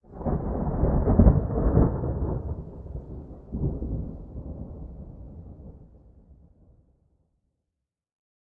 thunderfar_20.ogg